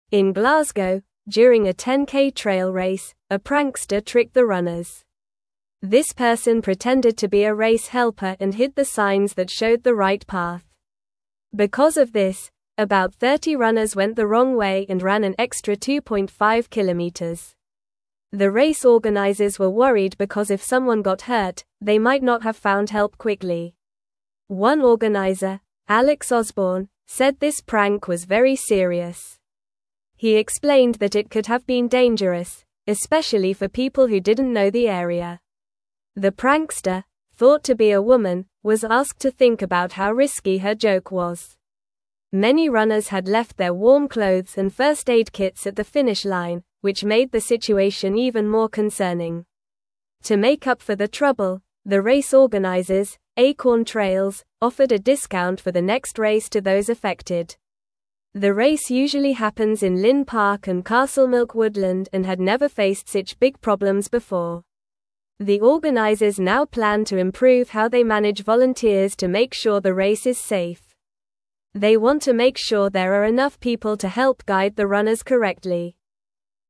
Normal
English-Newsroom-Lower-Intermediate-NORMAL-Reading-Trickster-Confuses-Runners-in-Glasgow-Race.mp3